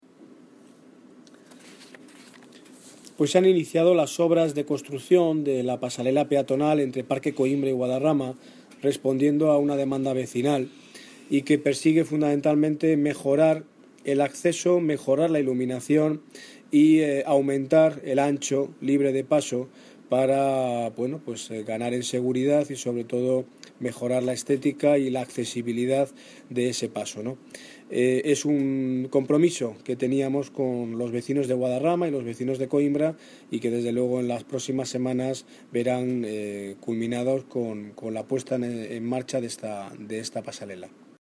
Audio - Daniel Ortiz (Alcalde de Móstoles) Sobre Pasarela